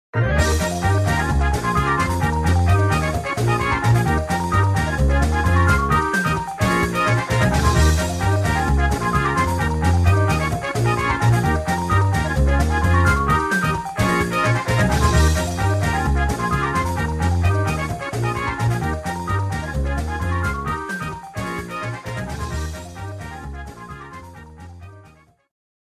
theme